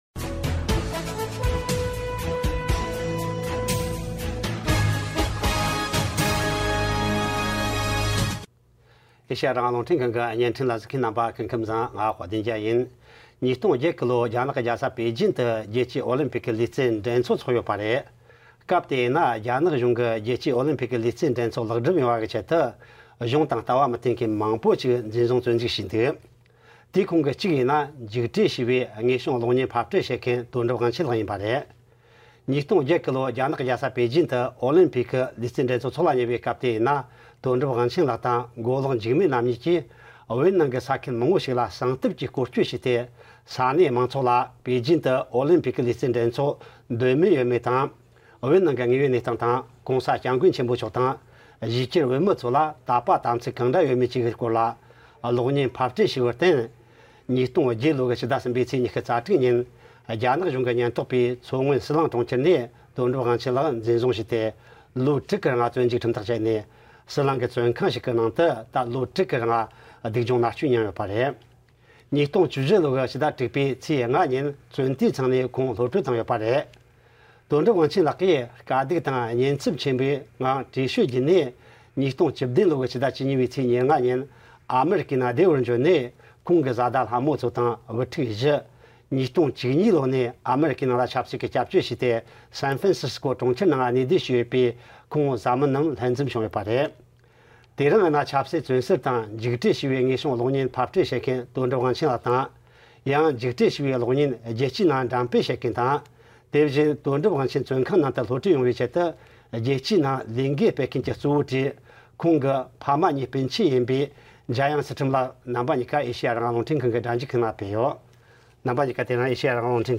འཇིགས་བྲལ་གློག་བརྙན་ཕབ་སྐྲུན་པ་དོན་གྲུབ་དབང་ཆེན་ལགས་སུ་བཅར་འདྲི།